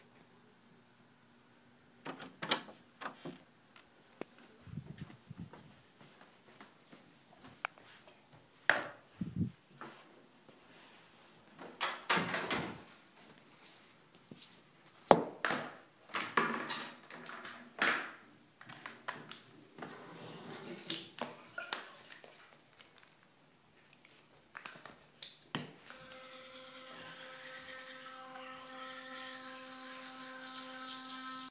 Field Recording #4
Sounds Heard: The doorknob turning and the door opening. You can hear walking across the hall and into another room. At the bathroom sink, you can hear a toothbrush holder meeting the sink. Next you hear the opening of the case and the electric toothbrush being put together. The water is turned on, toothpaste is added, and the brush is turned on. There are a few seconds of teeth brushing.
Brush.mp3